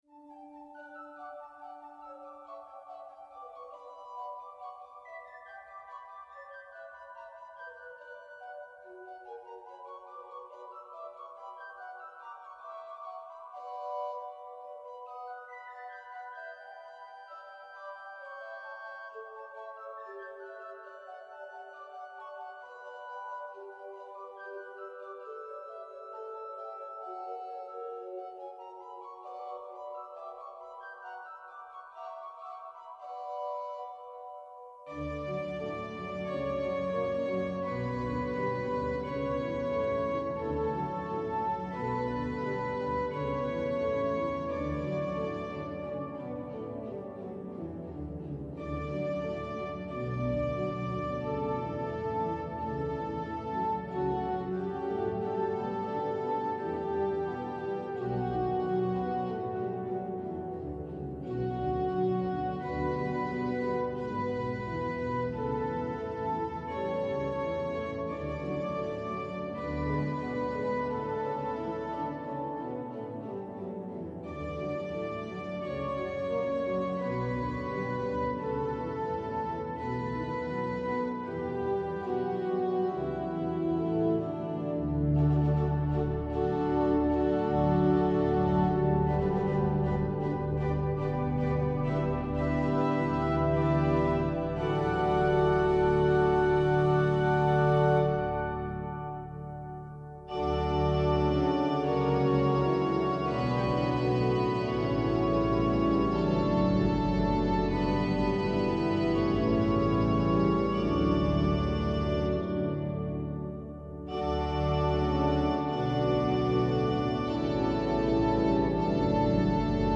Variaciones sobre un coral de Martín Lutero:
Fragmento grabado con el gran Órgano Allen de la Catedral de Valladolid.